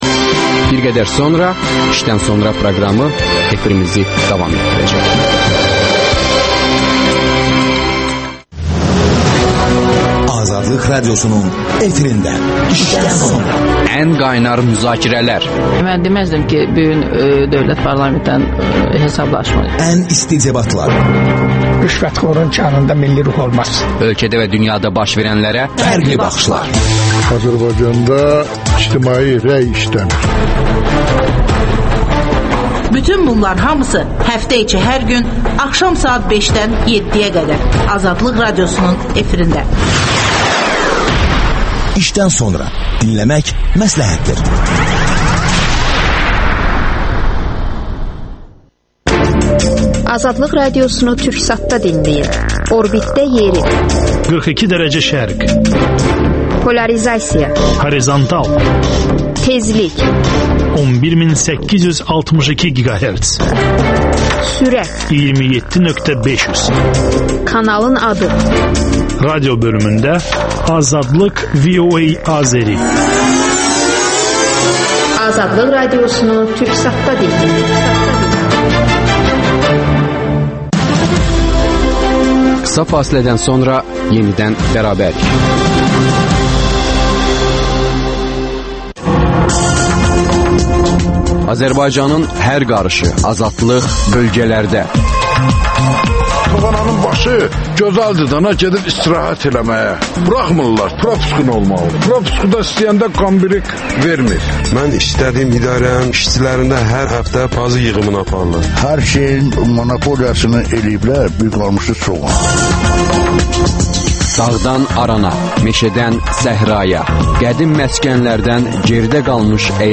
Almaniya Yaşıllar Partiyasının üzvü Volker Bek suallara cavab verəcək.